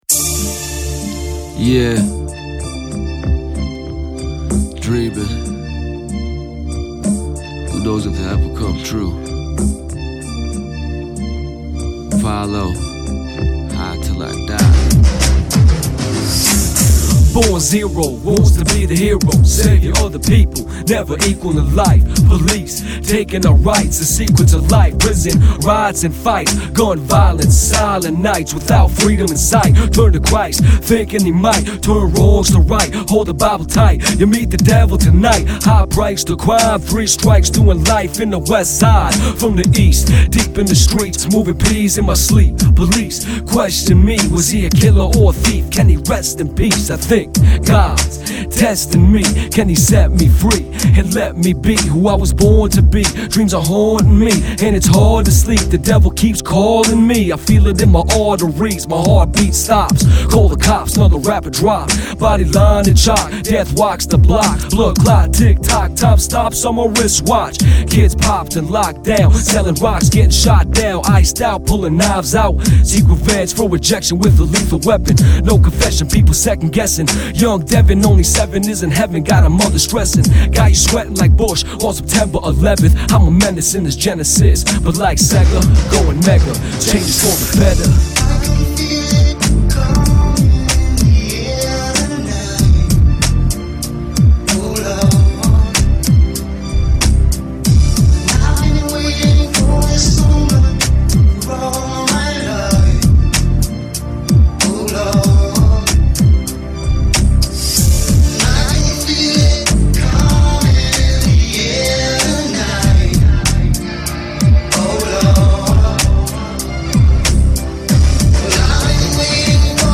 Canadian rapper